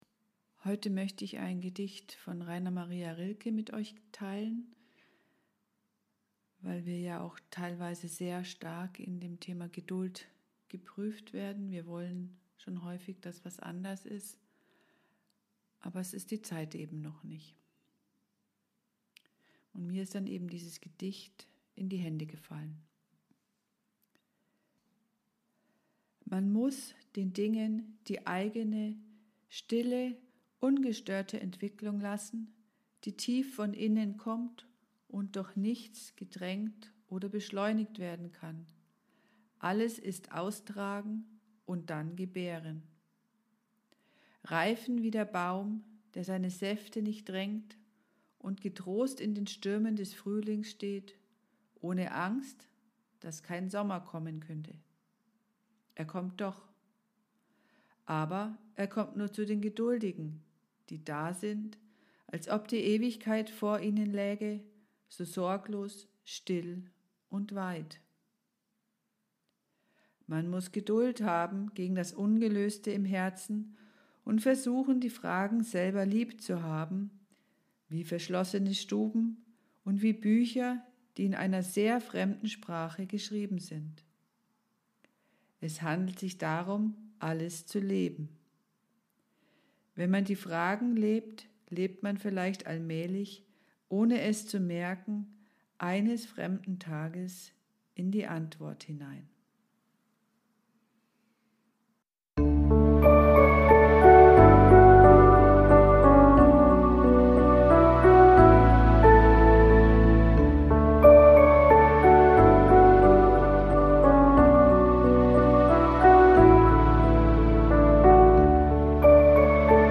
Gedicht von Rainer Maria Rilke